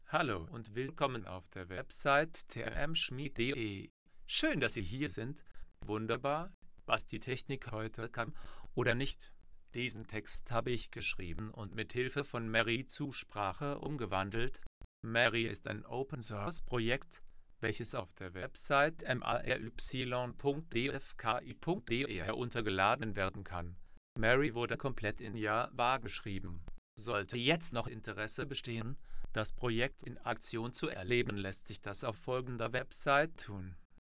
MaryTTS